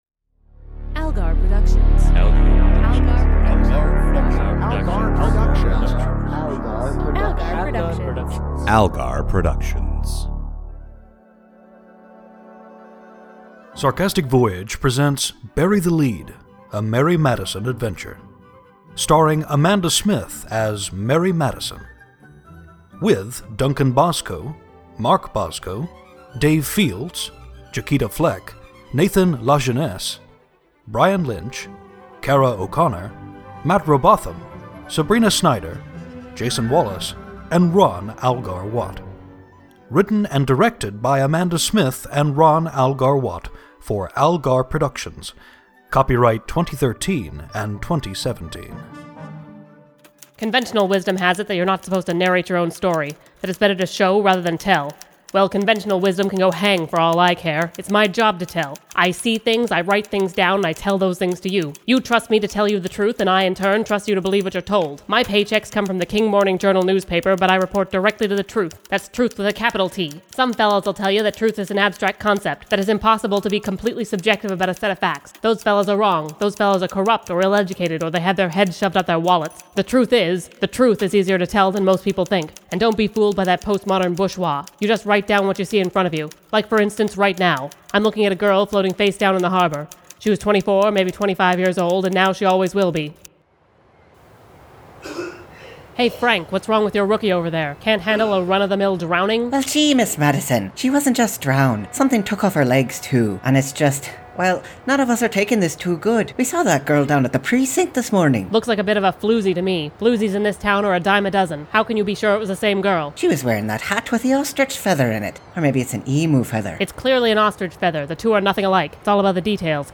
Bury the Lead represents a lot of firsts for us — it was the first full-length radio play we produced with the express purpose of being a radio play (as opposed to a series of barely-continuous serial episodes that loosely connected to form a story).
This 2017 remaster is largely for the sake of consistency across all the Mary stories — the script has not been changed at all.